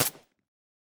sounds / weapons / _bolt / 762_3.ogg